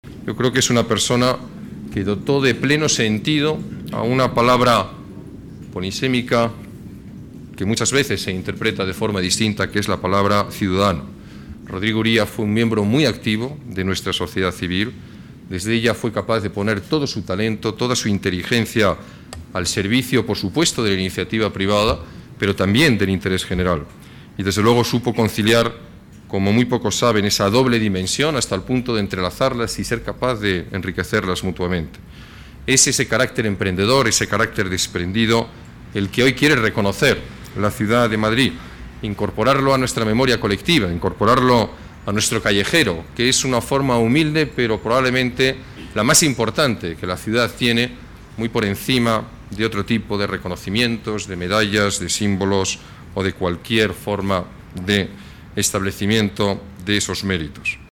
El alcalde, Alberto Ruiz-Gallardón, inaugura este espacio público, ubicado frente al número 187 de Príncipe de Vergara
Nueva ventana:Alberto Ruiz-Gallardón: palabras en el acto-homenaje a Rodrigo Uría.